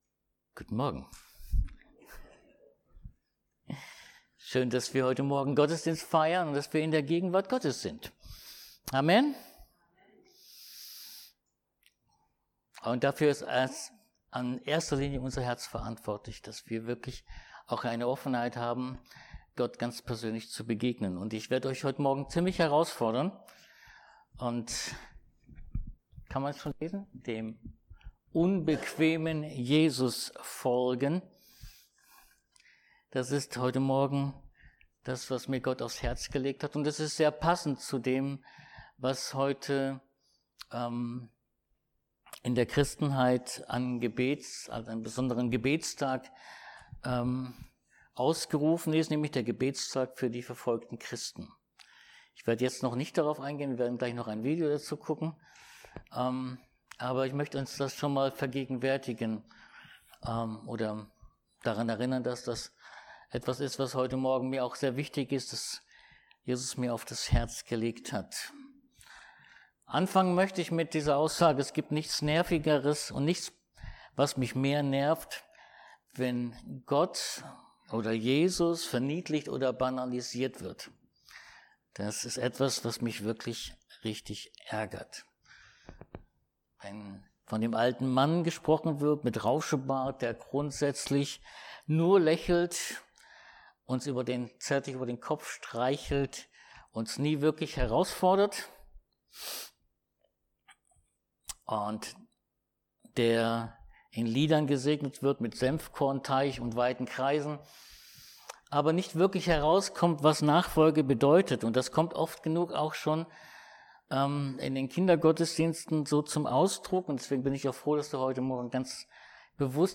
Mk 1,15 Dienstart: Predigt Jesus nachzufolgen bedeutet